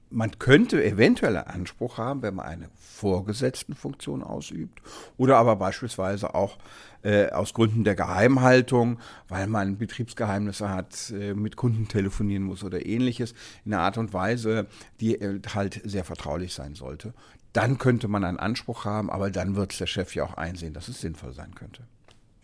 DAV, O-Töne / Radiobeiträge, Ratgeber, Recht, , , , ,